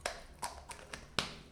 household
Closing Cap of Can of Shaving Foam 2